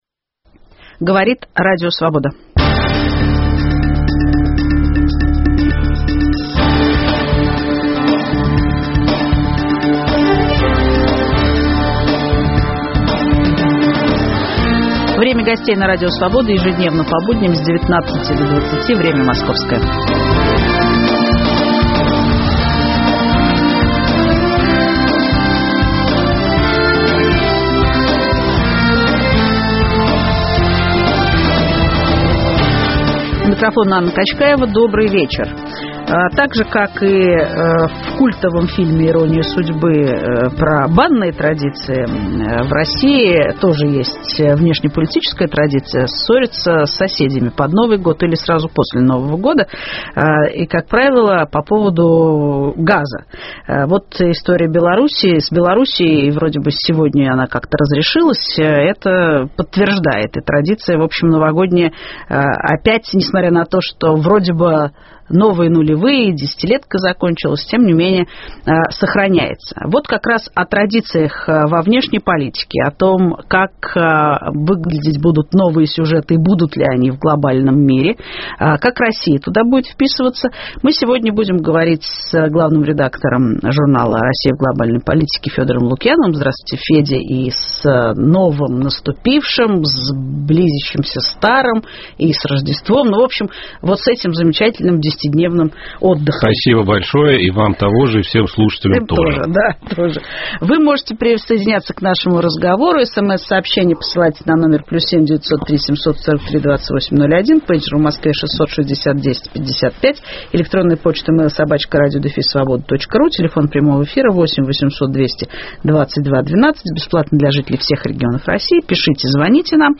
Известные и интересные люди ведут разговор о стране и мире, отвечают на вопросы в прямом эфире. Круг вопросов - политика, экономика, культура, права человека, социальные проблемы.